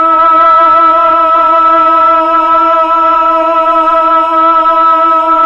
Index of /90_sSampleCDs/Roland LCDP09 Keys of the 60s and 70s 1/VOX_Melotron Vox/VOX_Tron Choir